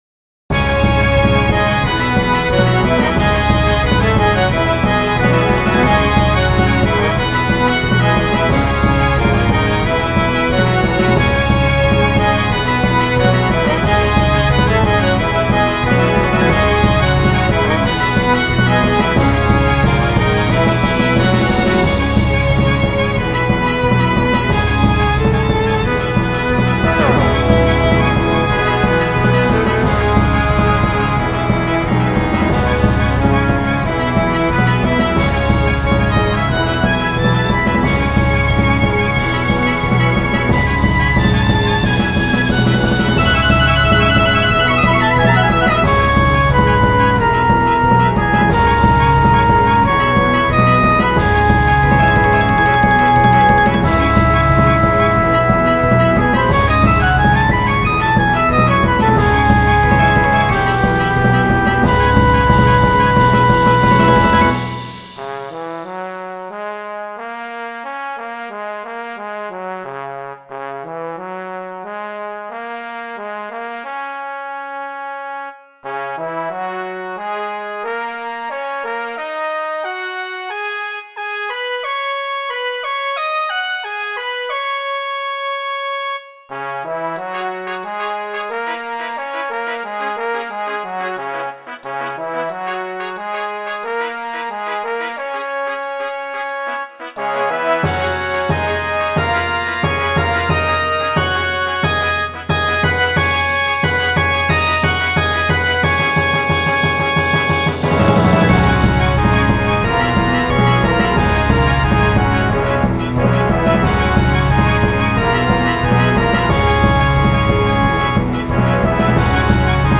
ジャンルOrchestra風(Classic風と見せかけて)
しかもそんなに激しくありません